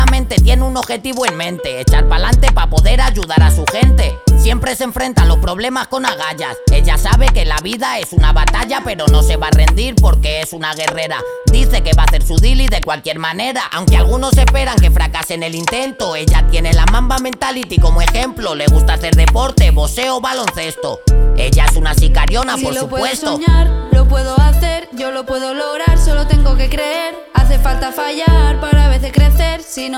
Жанр: Хип-Хоп / Рэп